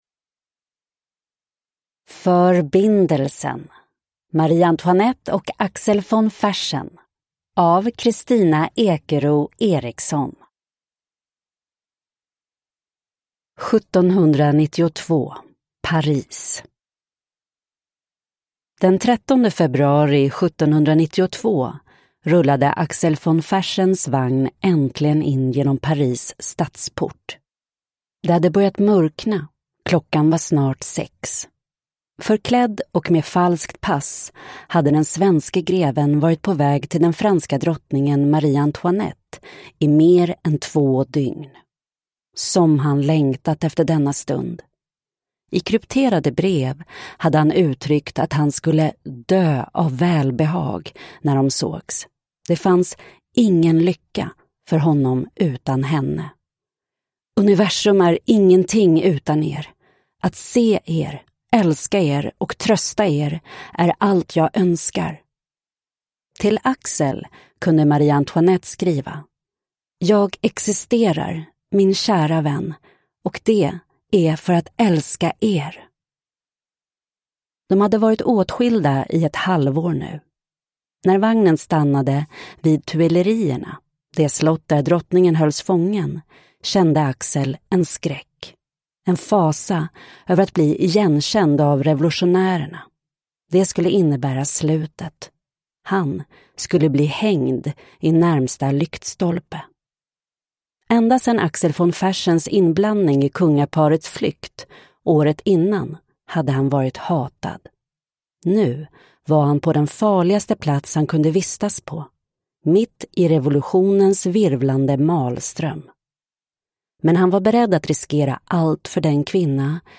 Förbindelsen : Marie-Antoinette och Axel von Fersen (ljudbok) av Kristina Ekero Eriksson